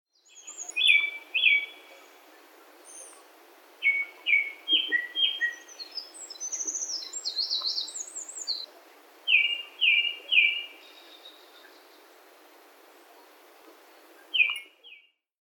◎　クロツグミ【黒鶫】　Japanese Thrush　スズメ目ヒタキ科ツグミ属　全長:22ｃｍ
初夏の森で朗らかにさえずる。さえずりは複雑で、他の鳥の声を取り入れる。
【録音①】　2021年5月24日　神奈川県　西丹沢　さえずり
「キョロン キョロン ツリリン キーコ キーコ」などと複雑にさえずる
※　出だしと中ごろにミソサザイの声が聞ける